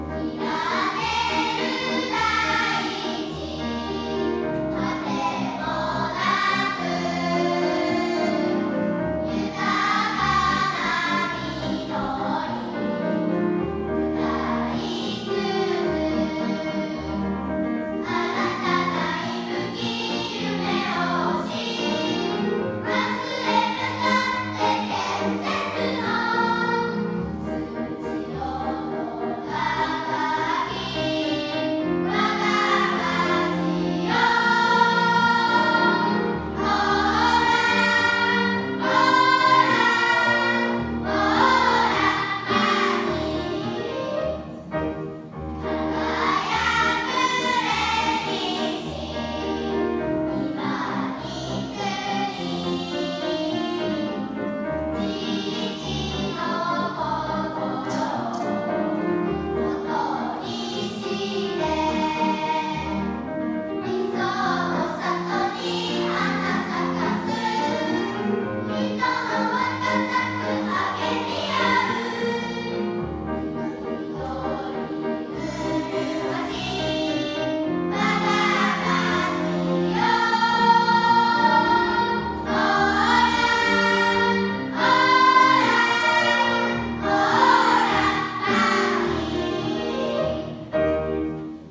邑楽町町歌【歌：おうら少年少女合唱隊sing！（町制50周年記念式典より）】